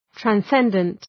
{træn’sendənt}
transcendent.mp3